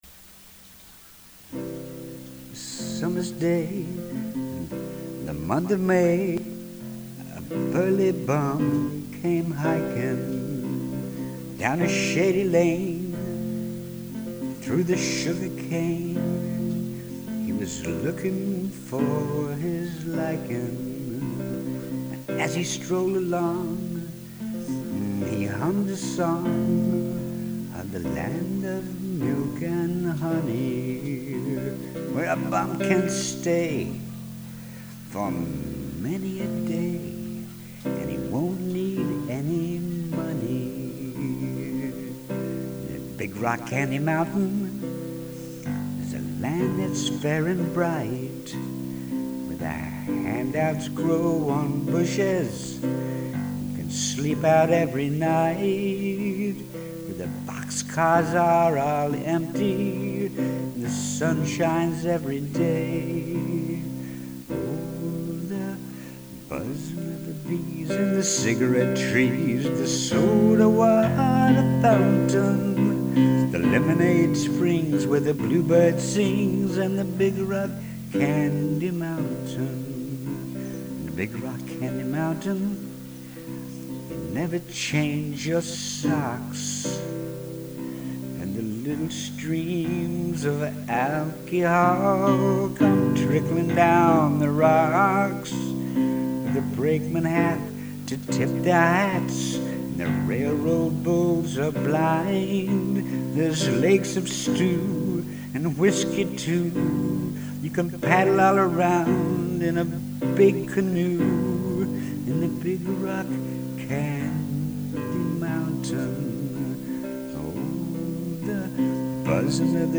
listening was like a lullaby for me this evening.